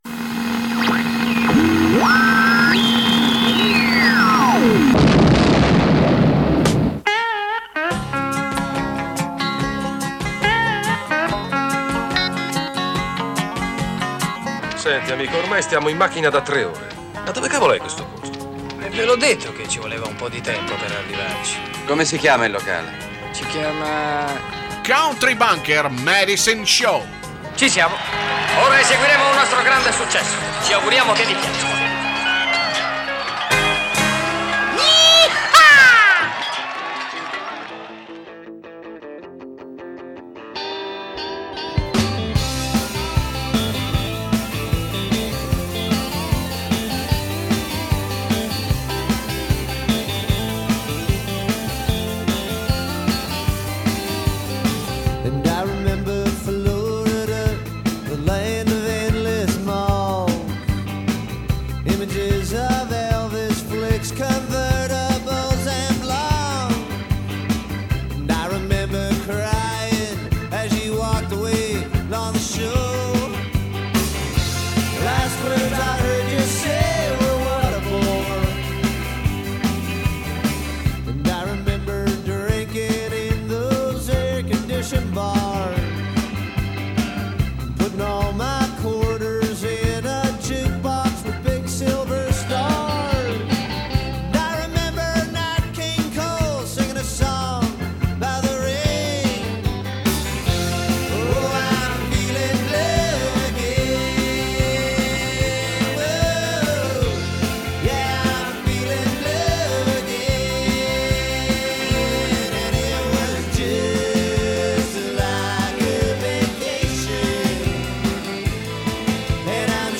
Both Kinds of Music: Country & Western